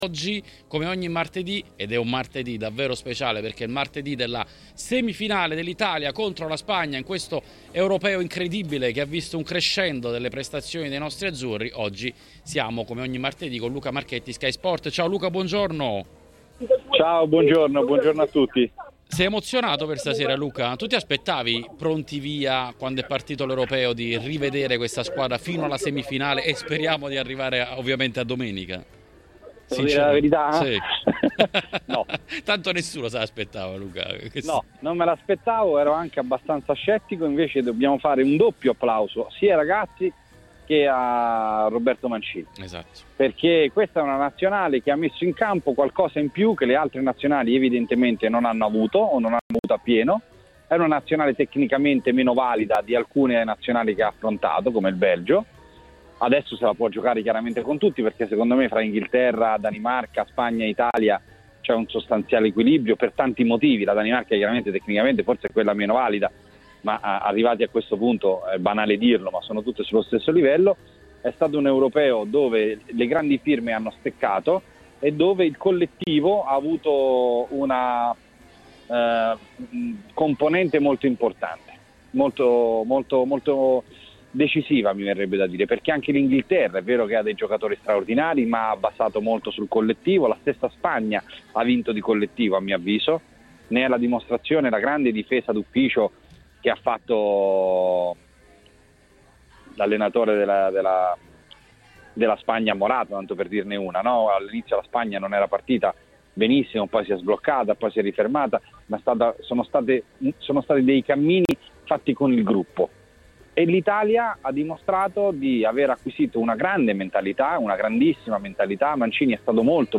interviene nel consueto editoriale del martedì mattina su TMW Radio: